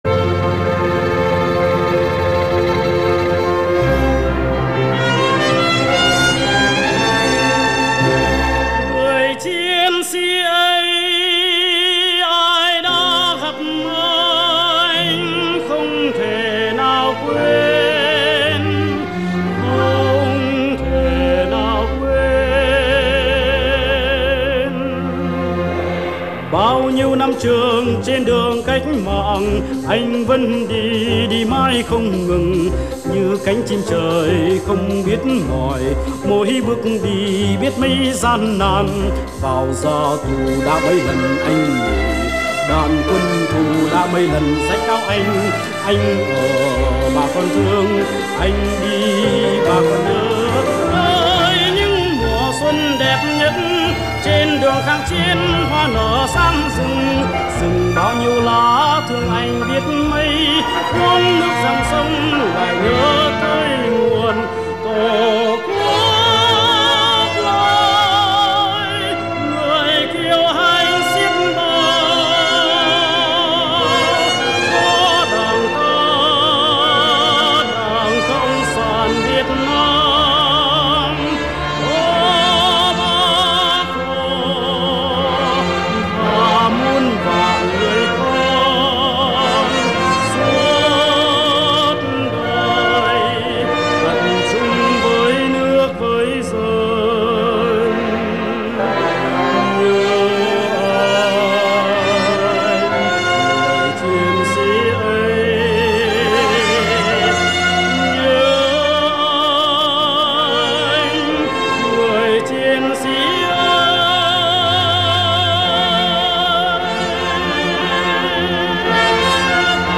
giọng nam cao hiếm có
Với chất giọng ténor khỏe khoắn, truyền cảm